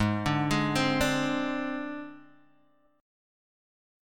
G#Mb5 chord